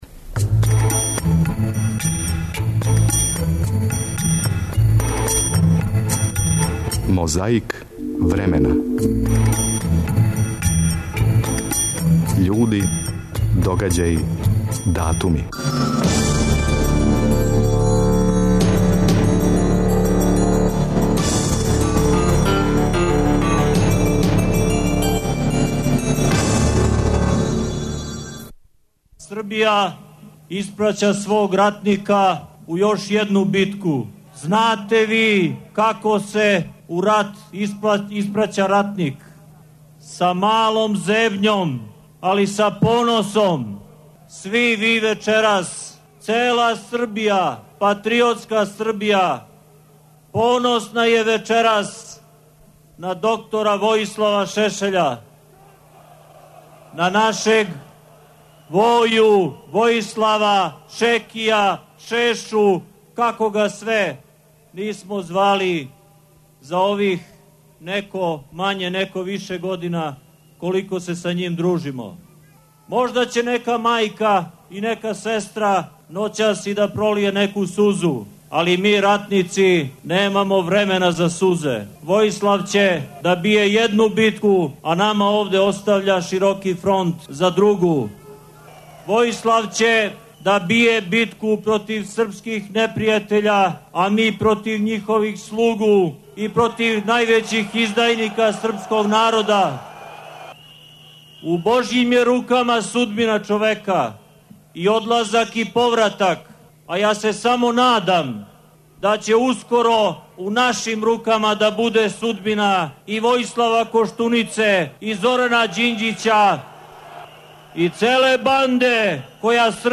Када се живело у СФРЈ, 21. фебруара 1974. године проглашен је Устав СФРЈ. Свечано у ентеријеру, док је напољу падала киша, али звучници су јој пркосили и преносили.